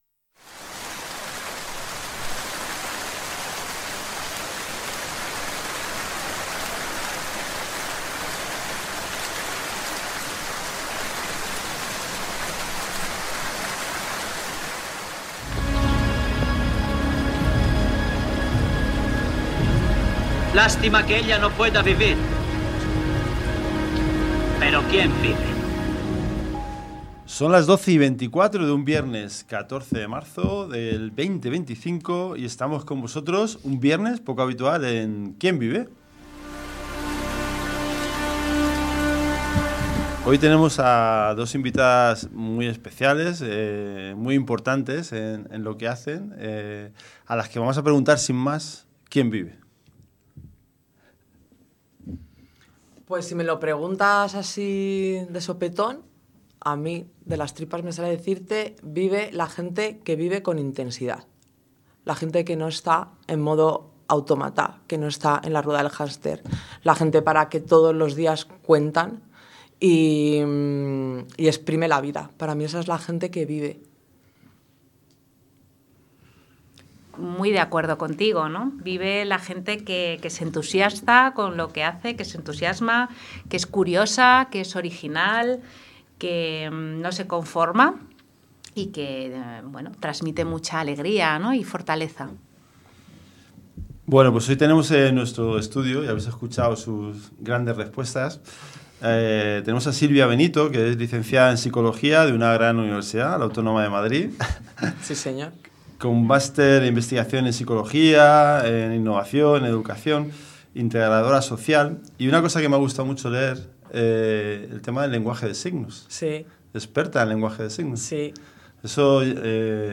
En esta entrevista, descubrimos cómo funciona el centro, los retos que enfrentan en la educación de estudiantes con altas capacidades y el impacto que tiene este programa en su desarrollo.